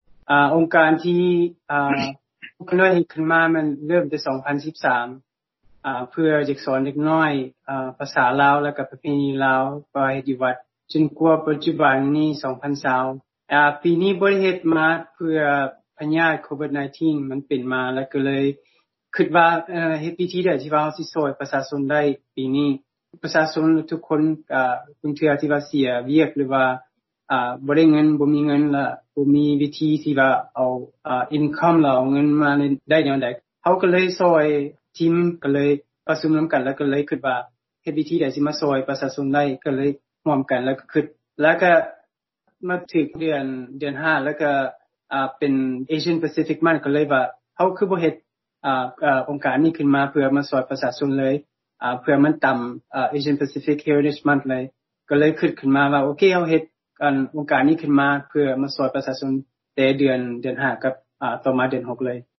ຢູ່ໃນການໃຫ້ສໍາພາດຜ່ານທາງວີດິໂອກັບວີໂອເອສູ່ຟັງວ່າ: